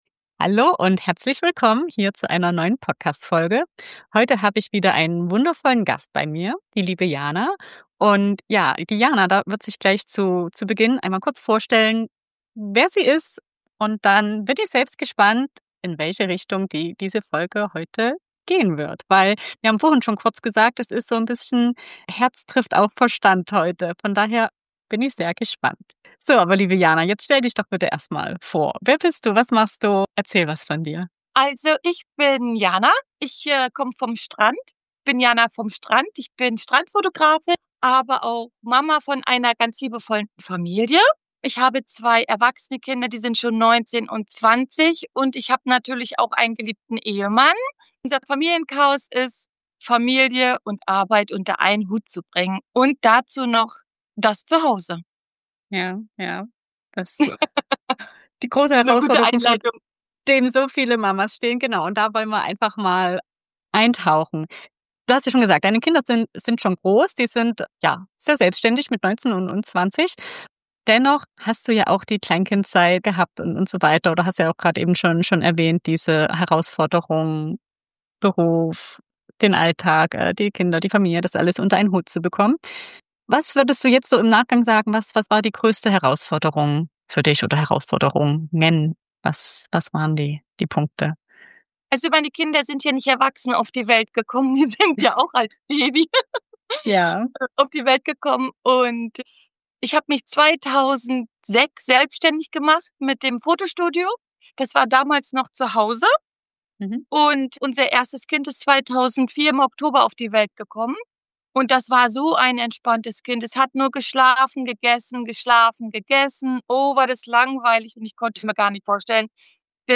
In dieser Folge spreche ich mit einer Mama, deren Kinder heute erwachsen sind.